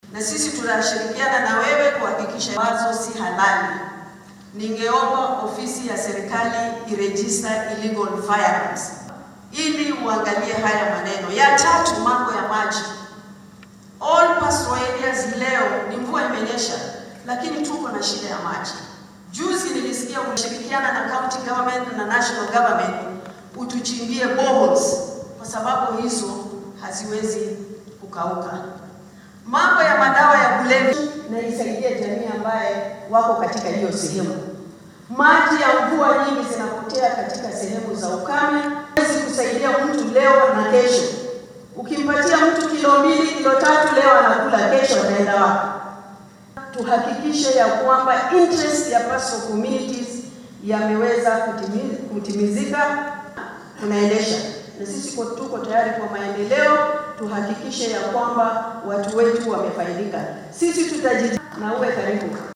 Hadalkan ayuu jeediyay xilli uu xarunta shirarka caalamiga ee KICC ee magaalada Nairobi uu ka furay shirka siyaasiyiinta ka soo jeedo ismaamullada xoolo dhaqatada .
Senatarka ismaamulka Isiolo Faadumo Duullo ayaa madaxweynaha la wadaagtay caqabado ay doonayaan in dowladda ay wax ka qabato sida biyaha iyo amni darrada.
Senatarka-Isiolo.mp3